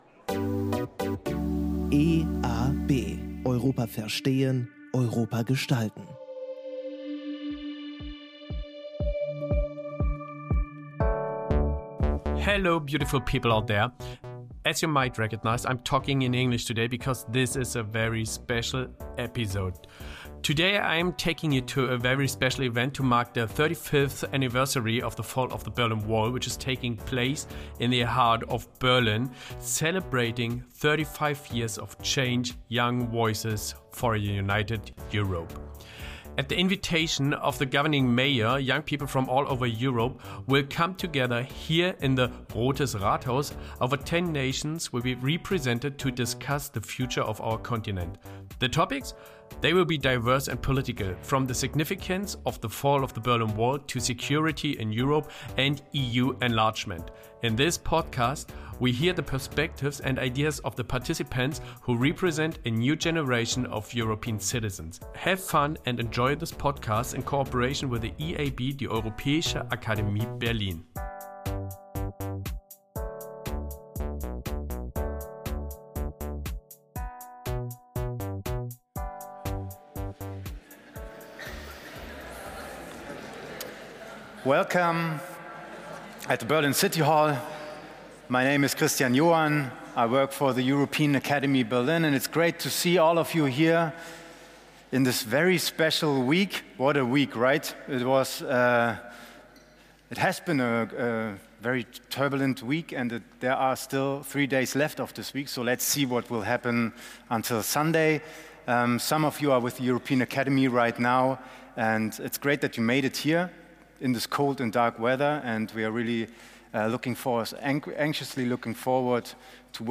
Here, young people from across Europe have gathered to mark the 35th anniversary of the fall of the Berlin Wall. This episode captures the voices and perspectives of youth from over ten countries as they engage in discussions about Europe’s future, covering topics like security, defense policies, and the celebration of Europe’s cultural diversity.
Their insights lay the foundation for dynamic conversations among participants in a World Café format. Young Europeans share their thoughts on the symbolic power of the Berlin Wall, the expansion of the EU, and what European unity means in today’s world.